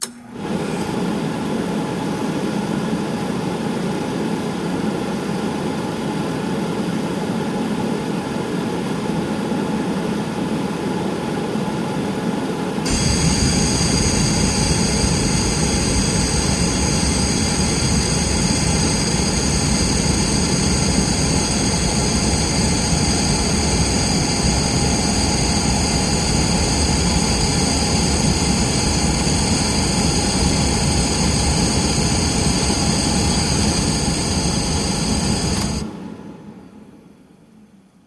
Звуки кремации
Запуск электрической печи в современном крематории